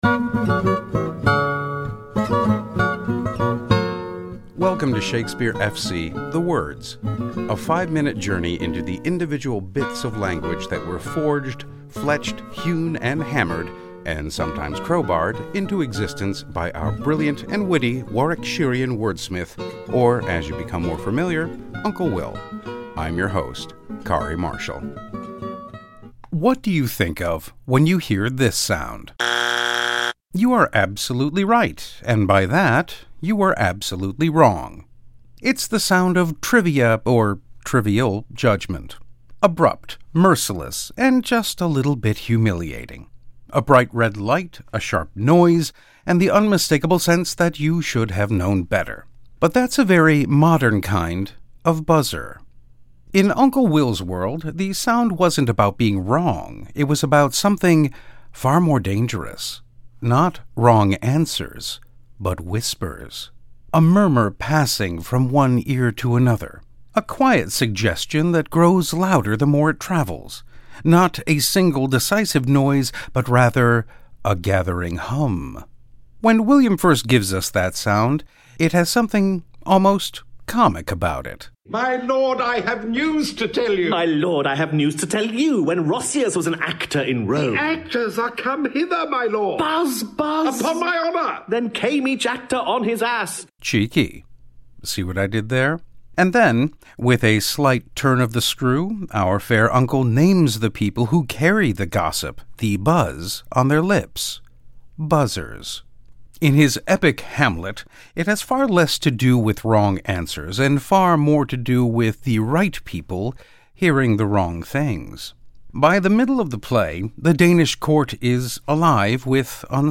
What do you think of when you hear this sound: (buzzer noise) - you are absolutely right.